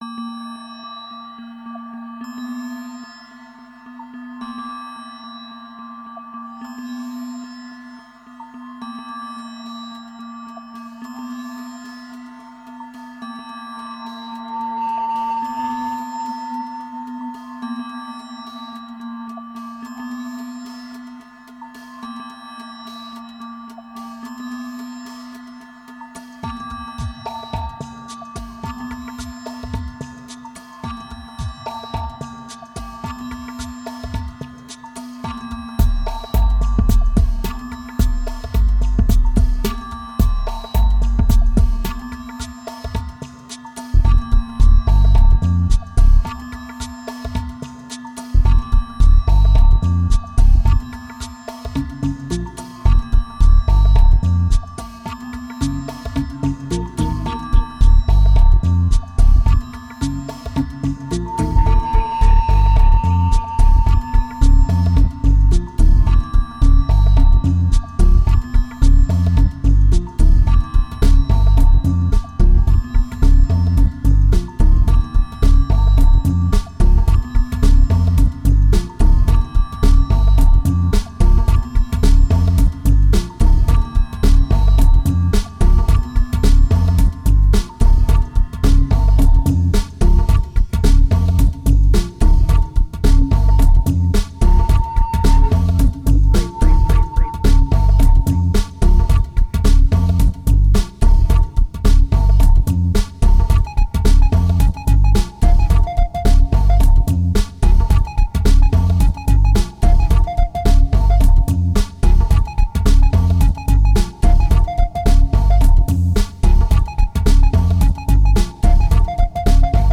2458📈 - 5%🤔 - 109BPM🔊 - 2010-07-03📅 - -439🌟